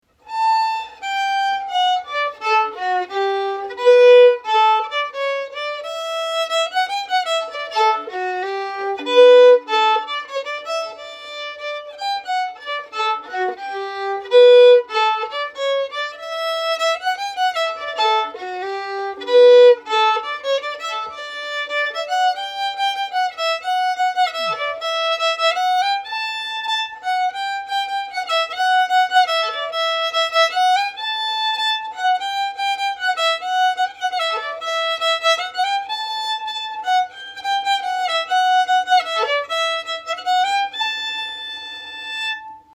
Key: D
Form: Jig
M:6/8
Genre/Style: Contradance